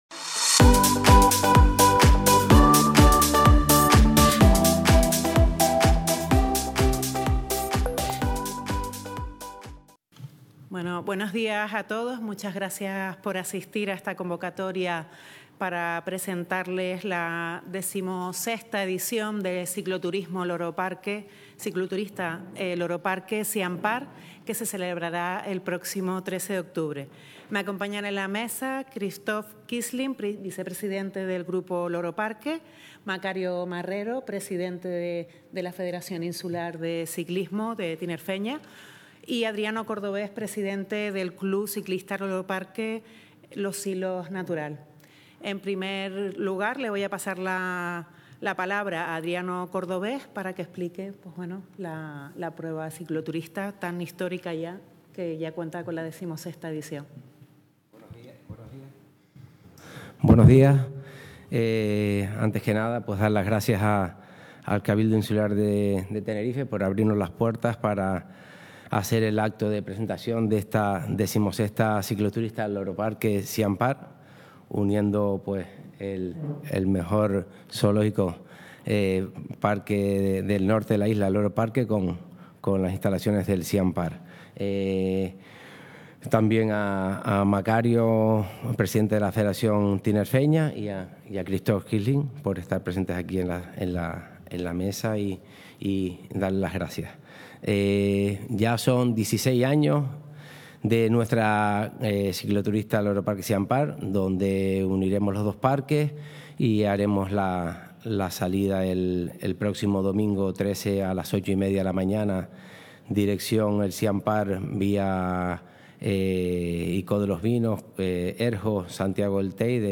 La XVI edición de la Cicloturista Loro Parque-Siam Park fue presentada hoy, 8 de octubre, en el Salón Noble del Cabildo de Tenerife.